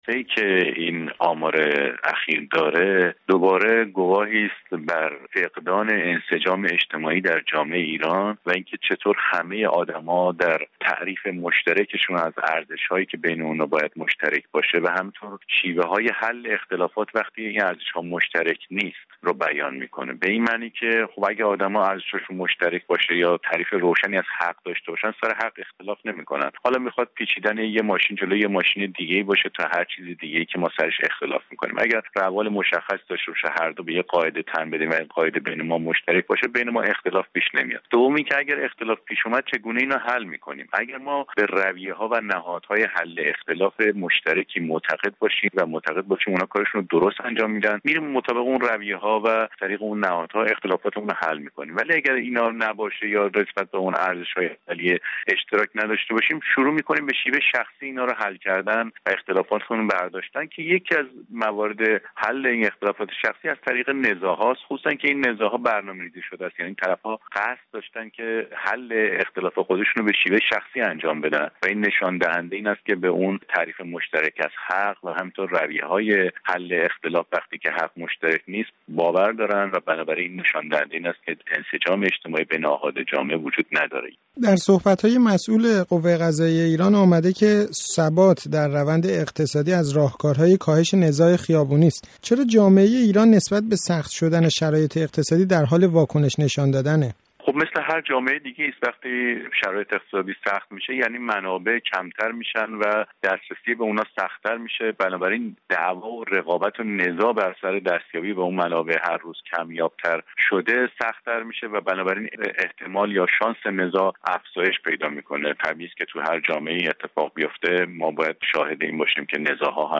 گفت و گو
جامعه شناس